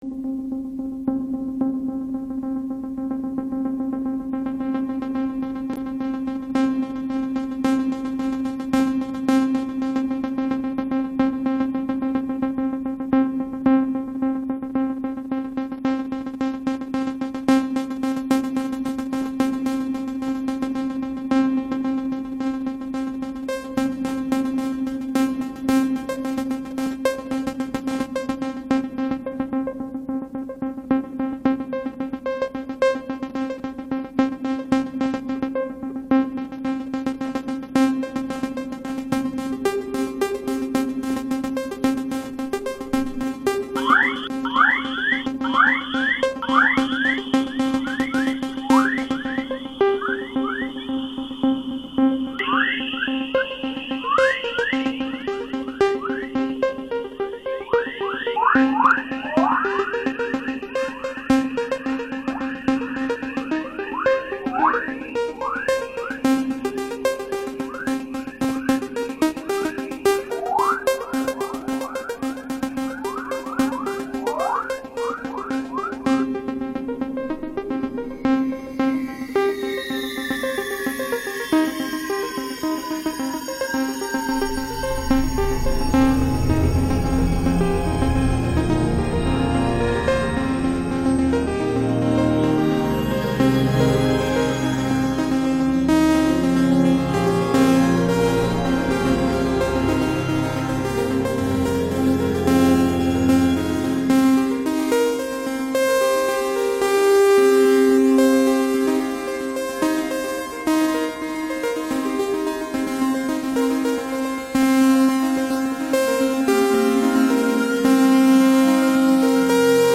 is a monthly experimental radio show focusing on sounds we’re exposed to every day in the public and domestic spheres, using field recordings, amplified and toys and live sources such as am/fm radio.